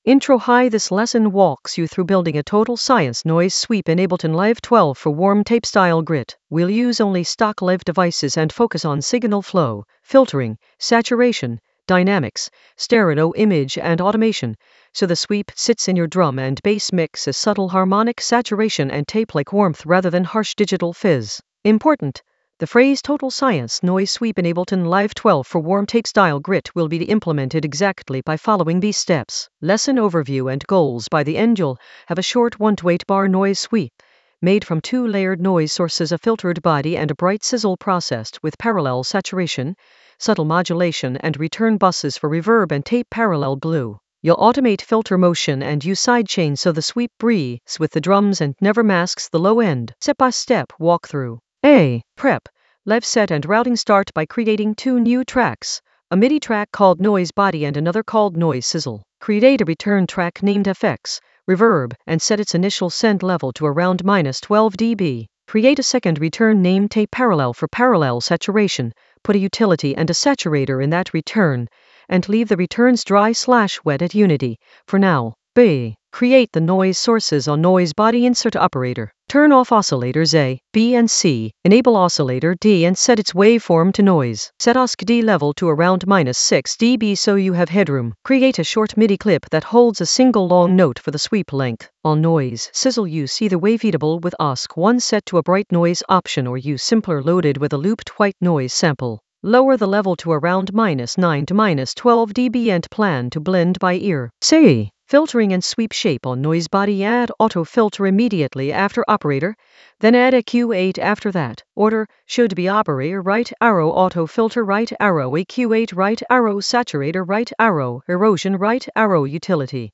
An AI-generated intermediate Ableton lesson focused on Total Science noise sweep in Ableton Live 12 for warm tape-style grit in the Mixing area of drum and bass production.
Narrated lesson audio
The voice track includes the tutorial plus extra teacher commentary.